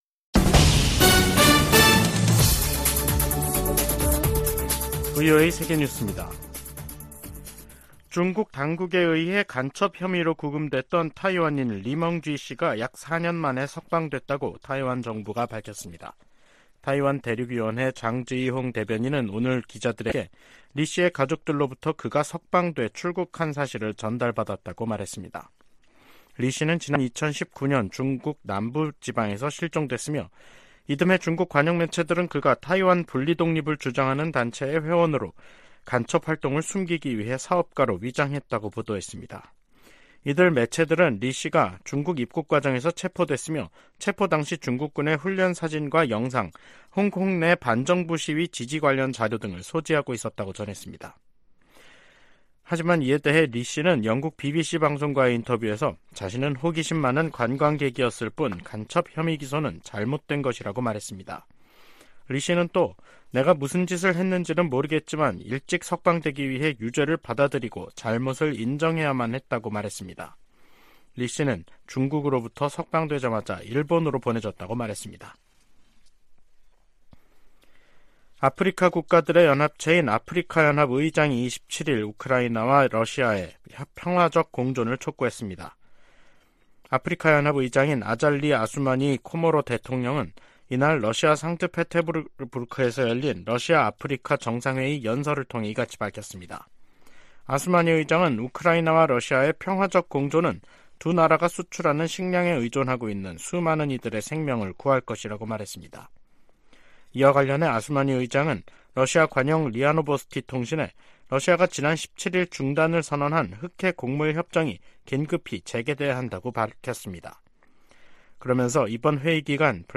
VOA 한국어 간판 뉴스 프로그램 '뉴스 투데이', 2023년 7월 27일 3부 방송입니다. 김정은 북한 국무위원장이 러시아 국방장관과 함께 '무장장비 전시회-2023'를 참관했습니다. 백악관은 북한과 러시아의 무기 거래 가능성에 관해 누구도 러시아의 우크라이나 전쟁을 지원해서는 안된다고 강조했습니다. 조 바이든 미국 대통령이 7월 27일을 한국전 정전기념일로 선포하고, 미한동맹이 세계 평화와 번영에 기여하도록 노력하자고 말했습니다.